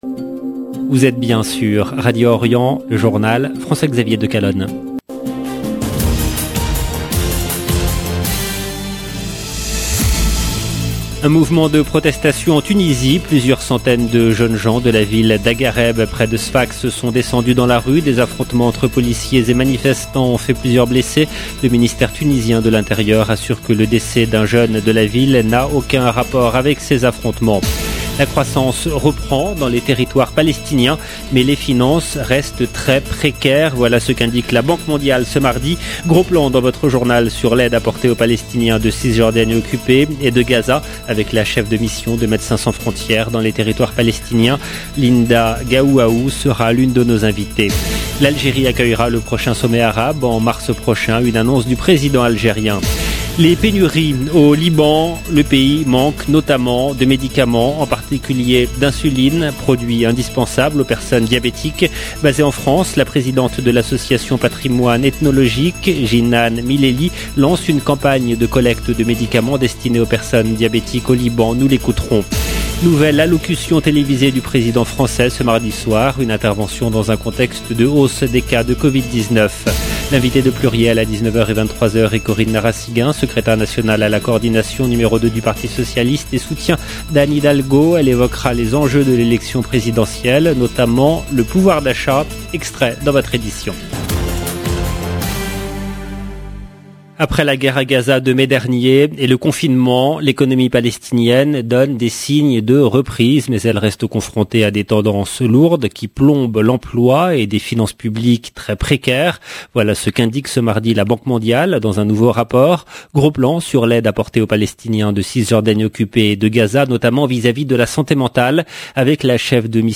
EDITION DU JOURNAL DU SOIR EN LANGUE FRANCAISE DU 9/11/2021